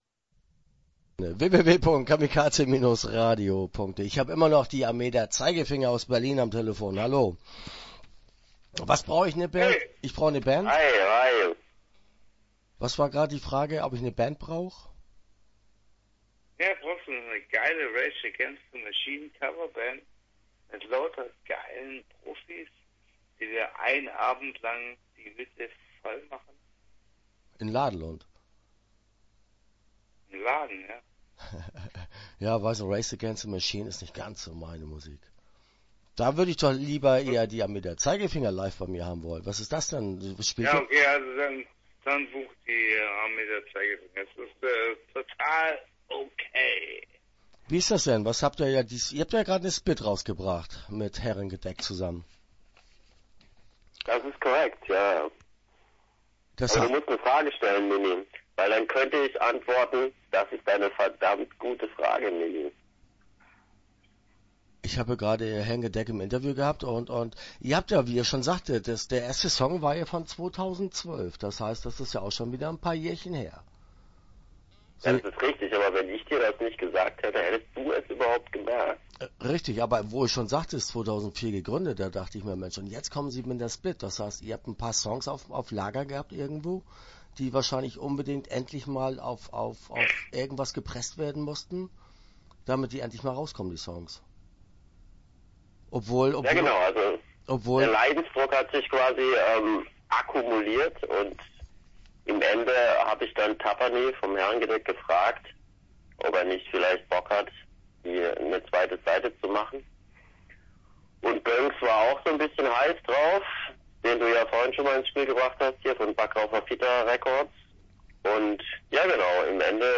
Die Armee der Zeigefinger - Interview Teil 1 (9:24)